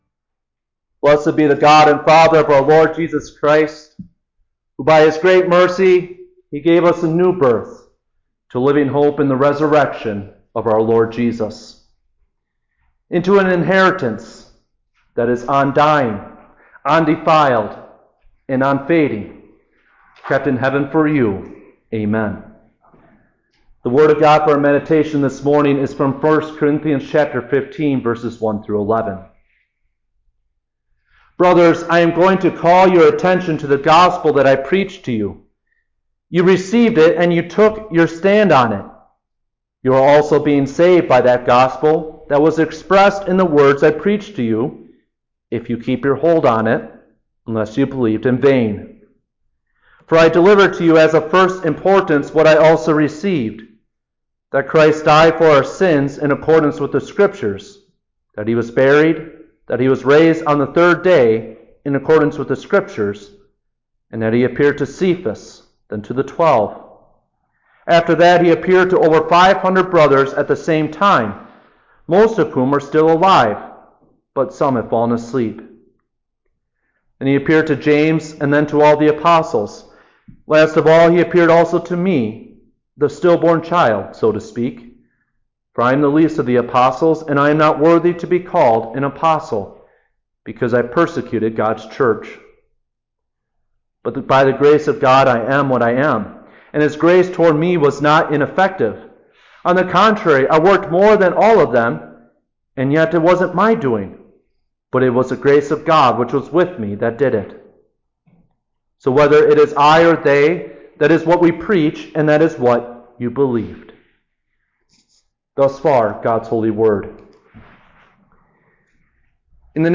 Easter-Sunday.mp3